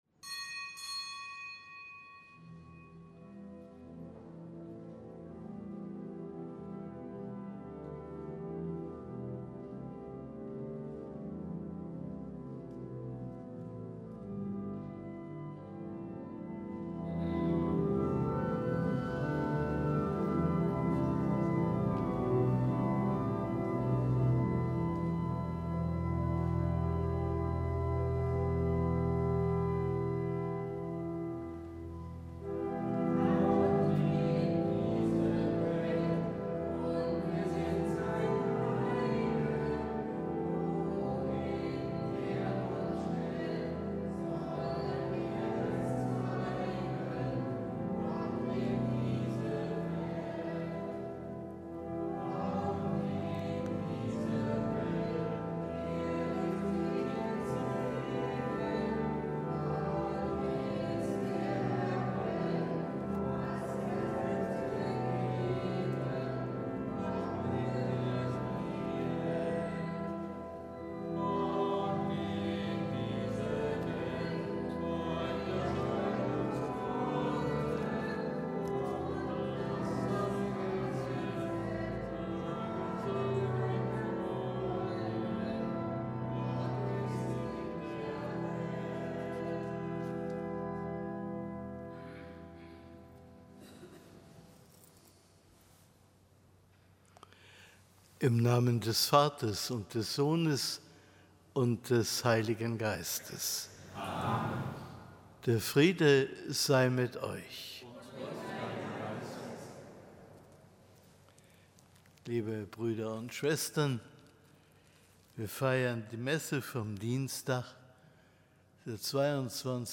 Kapitelsmesse aus dem Kölner Dom am Dienstag der zweiundzwanzigsten Woche im Jahreskreis. Zelebrant: Weihbischof Rolf Steinhäuser.